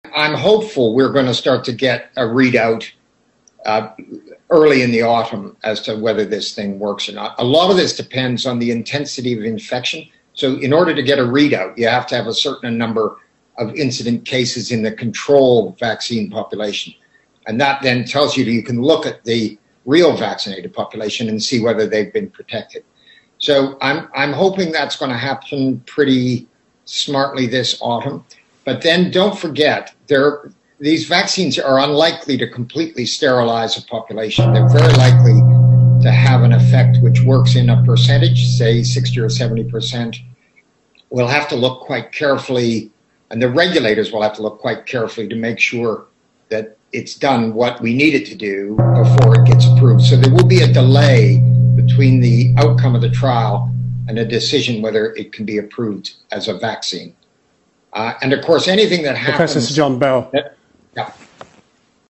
פליטת פה מחרידה של פרופסור ג’ון בל, ממפתחי הזריקה של אסטרה זניקה בראיון תקשורתי: ״אל תשכחו שהמחוסנים הם אנשים שהחיסון עיקר אותם.